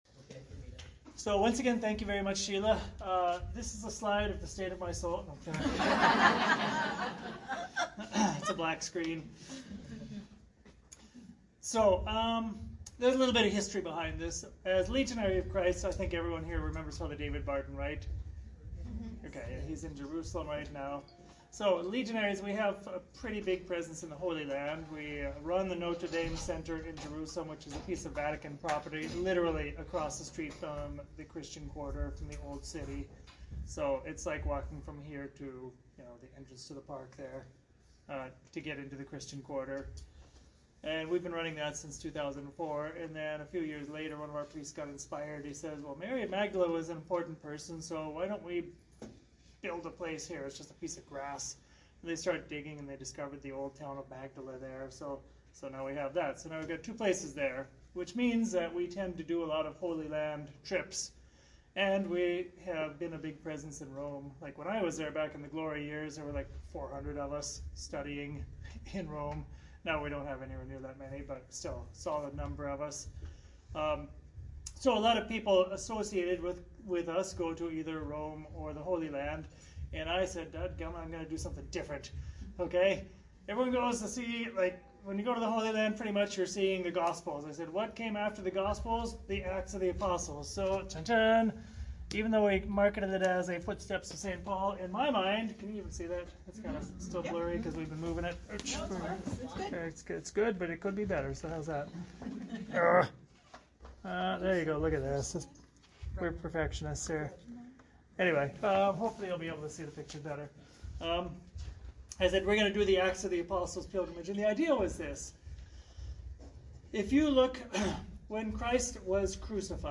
Pilgrimage Talk - RC NY Tri-State